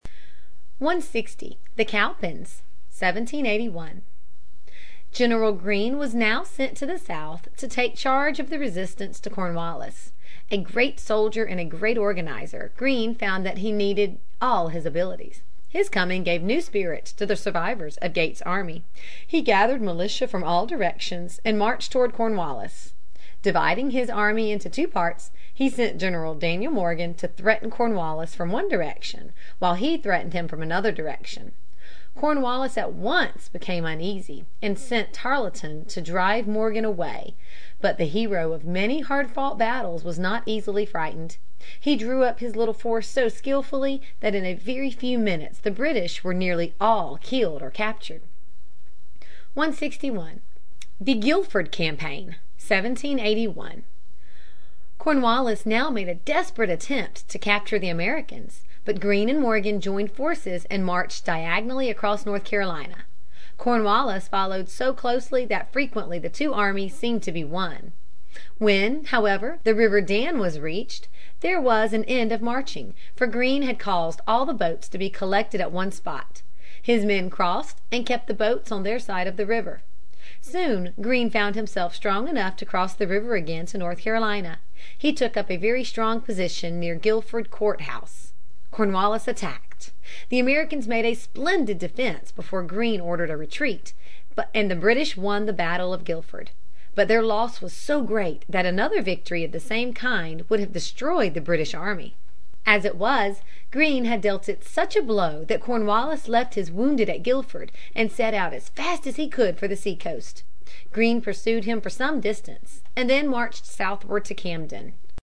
在线英语听力室美国学生历史 第53期:独立(2)的听力文件下载,这套书是一本很好的英语读本，采用双语形式，配合英文朗读，对提升英语水平一定更有帮助。